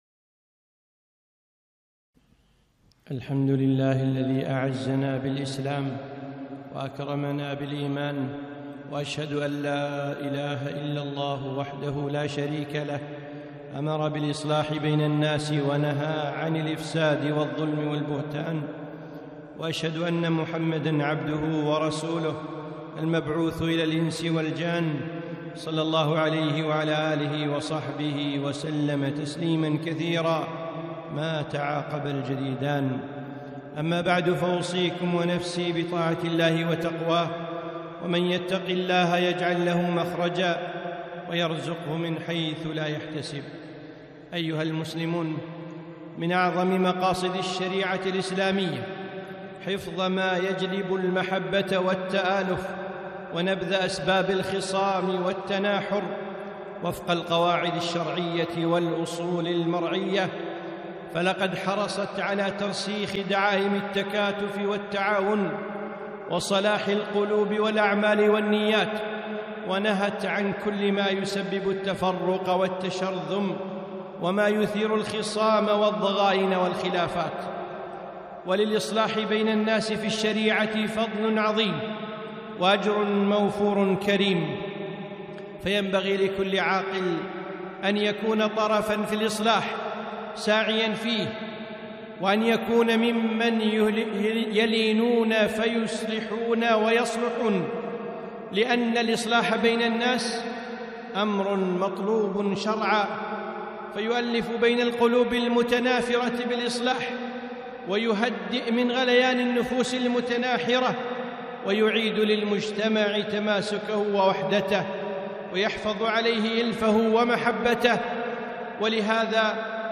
خطبة - الإصلاح بين الناس